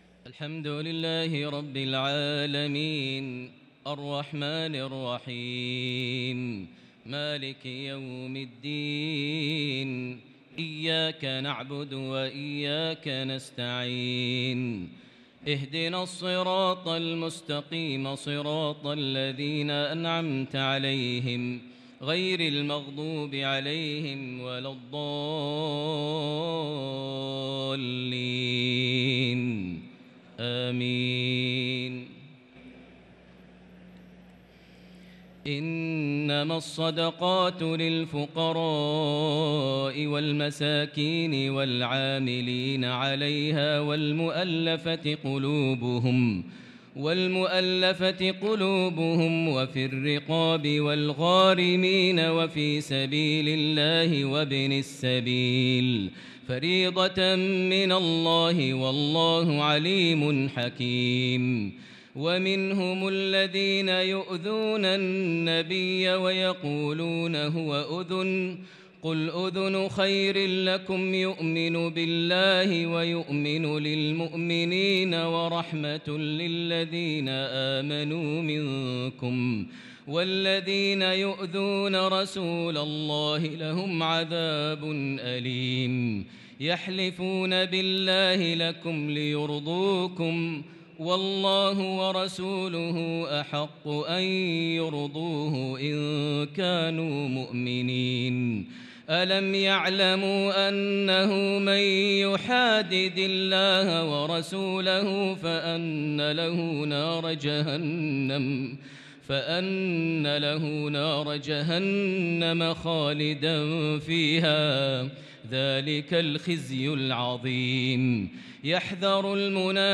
صلاة التراويح ليلة 14 رمضان 1443 للقارئ ماهر المعيقلي - الثلاث التسليمات الأولى صلاة التراويح